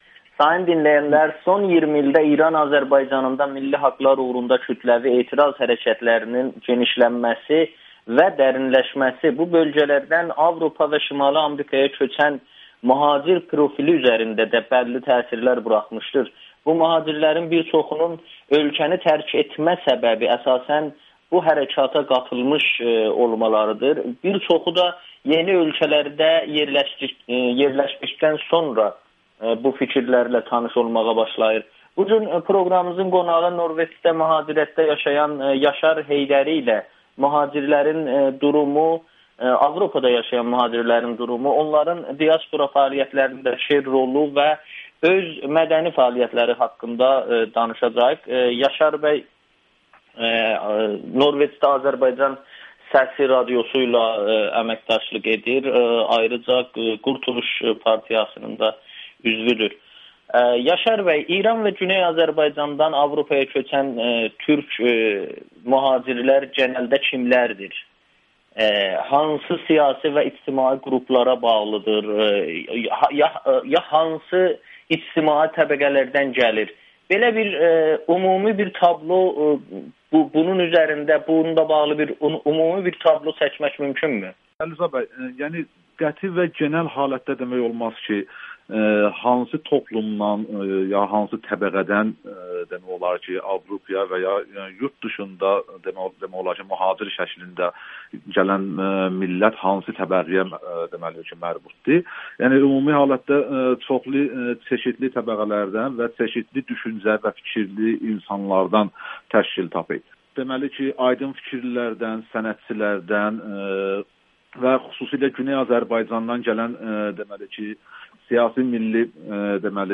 Avropada yaşayan güneyli mühacirlərin durumu [Audio-Müsahibə]